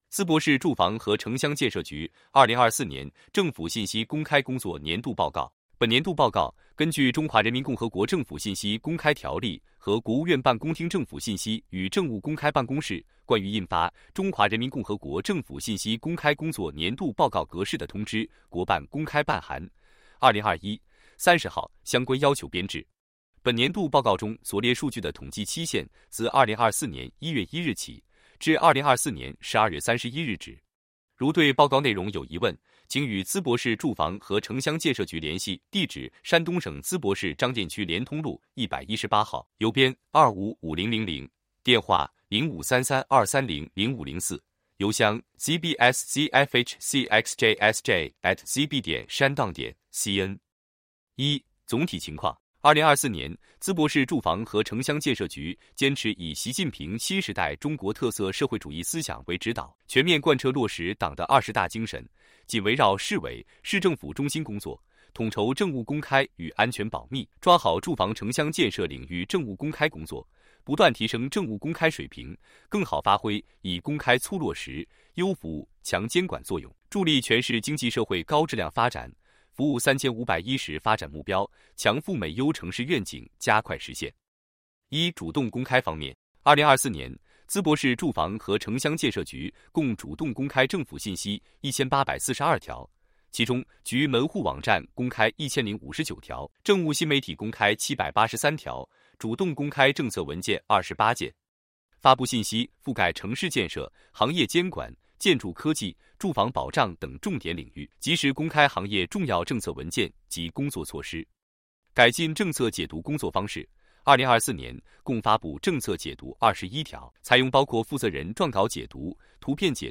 电子书 | H5 | 语音播报 | 图片解读 淄博市住房和城乡建设局 2023年政府信息公开工作年度报告 本年度报告根据《中华人民共和国政府信息公开条例》和《国务院办公厅政府信息与政务公开办公室关于印发<中华人民共和国政府信息公开工作年度报告格式>的通知》（国办公开办函〔2021〕30号）相关要求编制。